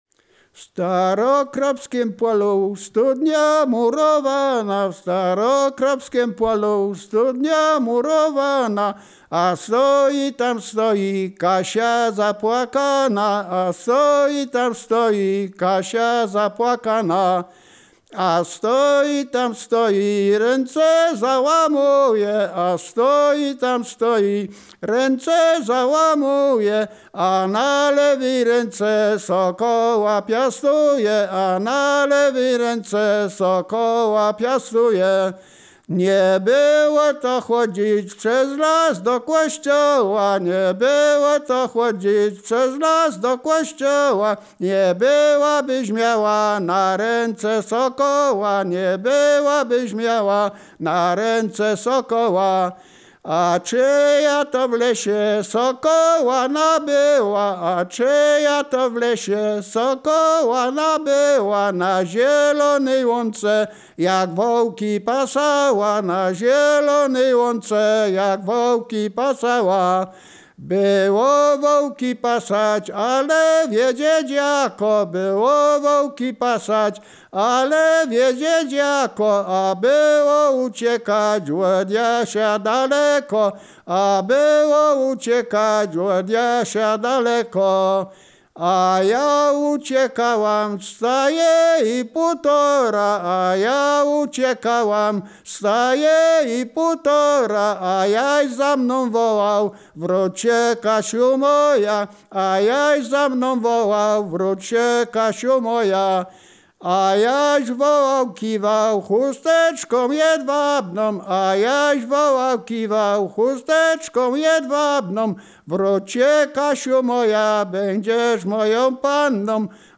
województwo wielkopolskie, powiat gostyński, gmina Krobia, wieś Stara Krobia
liryczne miłosne